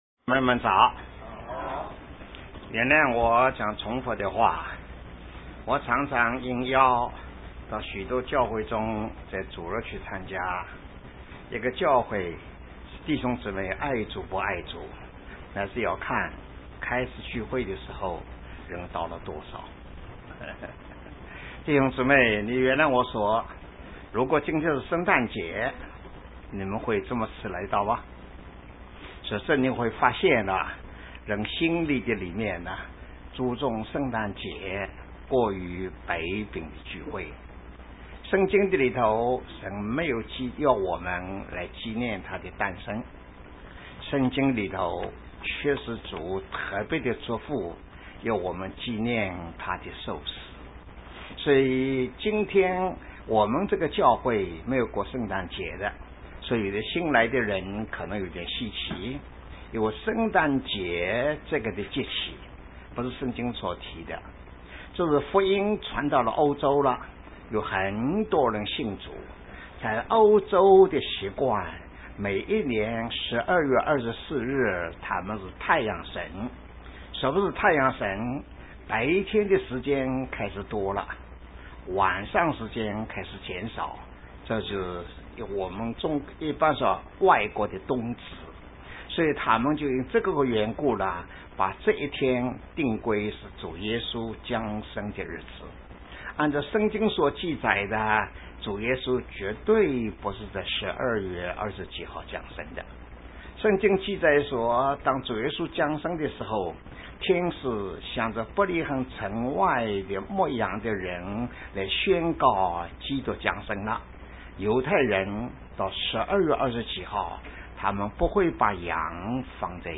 講道錄音 – 長島基督徒證主教會